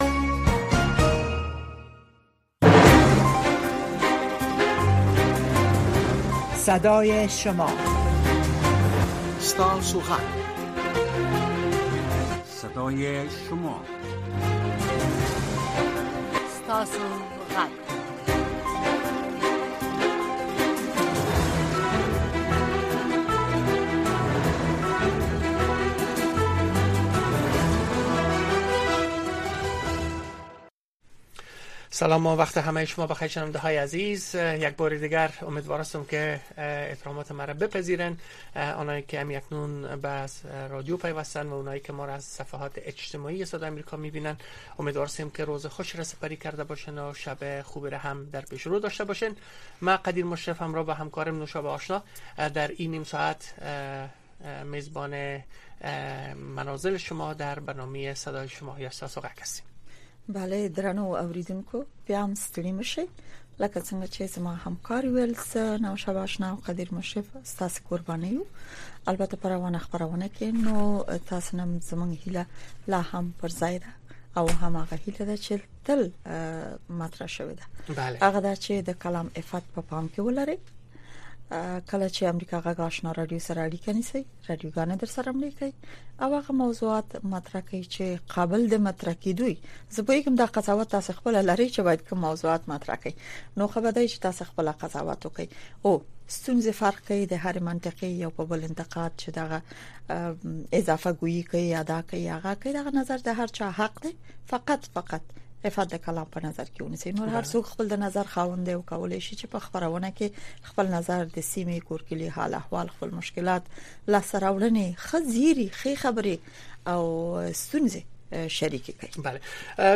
این برنامه به گونۀ زنده از ساعت ۱۰:۰۰ تا ۱۰:۳۰ شب به وقت افغانستان نشر می‌شود.